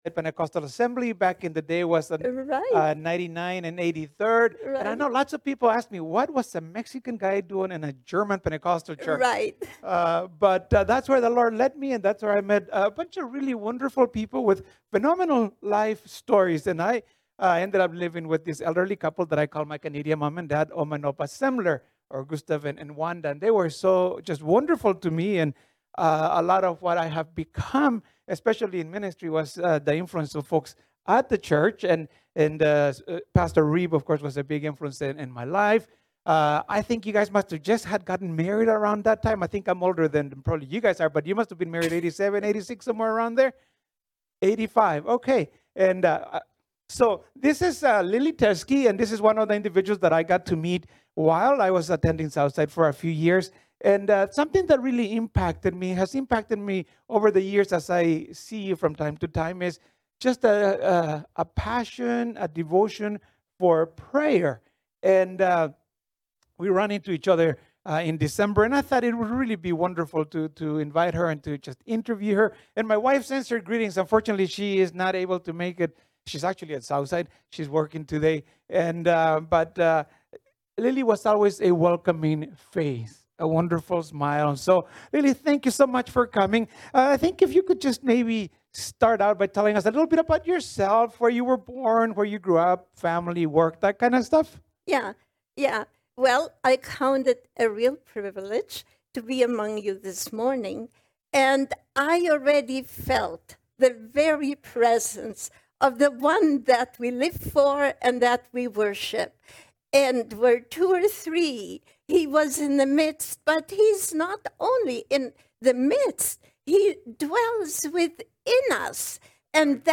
Prayer: An Interview